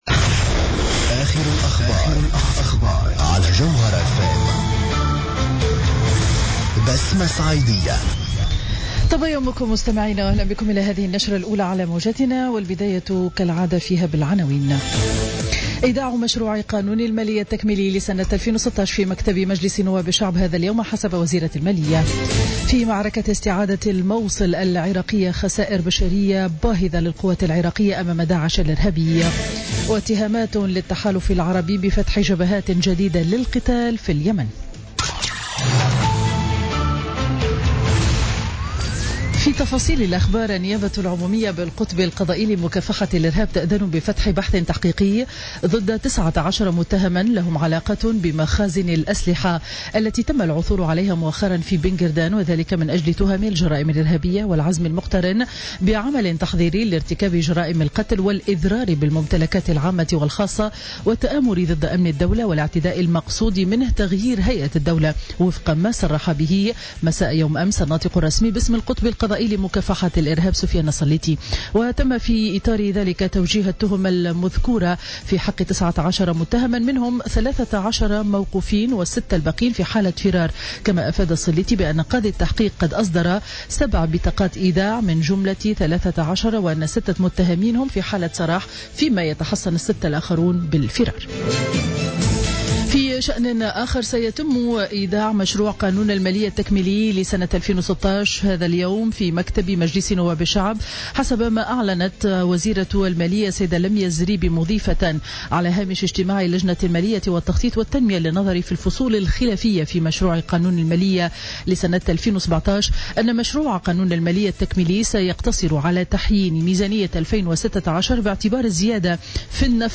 نشرة أخبار السابعة صباحا ليوم الجمعة 2 ديسمبر 2016